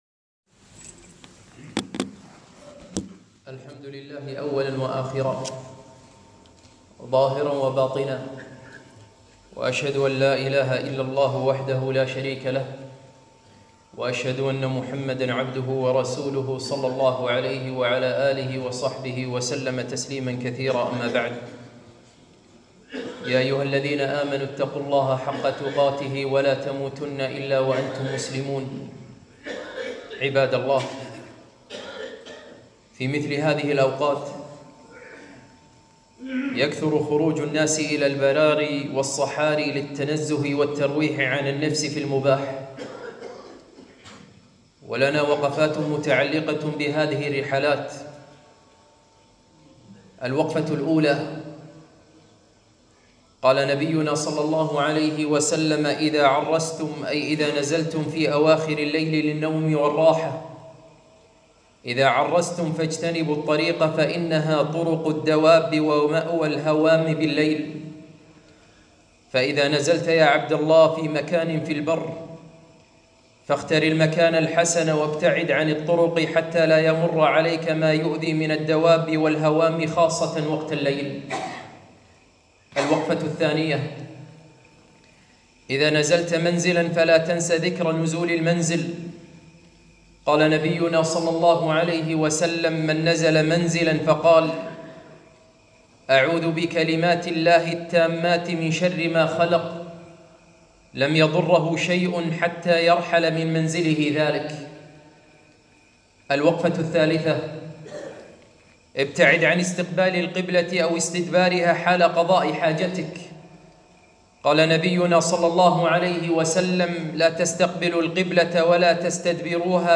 خطبة - ثلاث عشرة وقفة متعلقة بالرحلات البرية